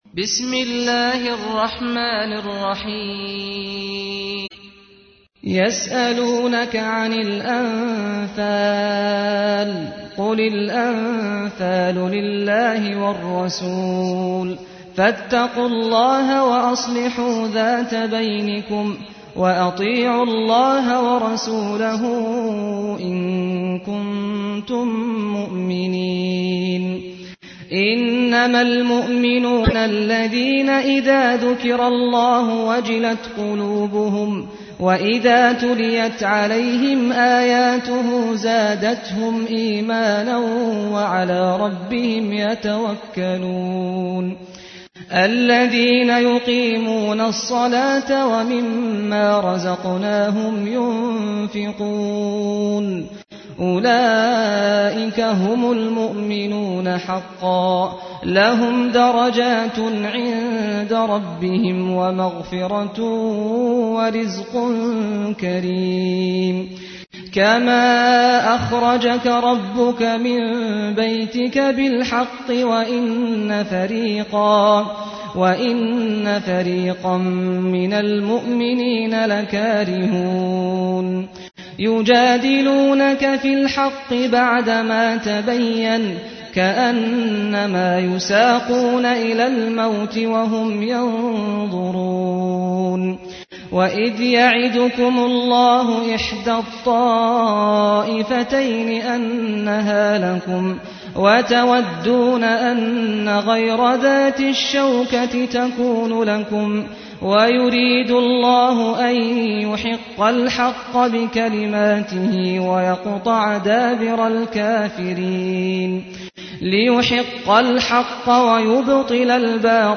تحميل : 8. سورة الأنفال / القارئ سعد الغامدي / القرآن الكريم / موقع يا حسين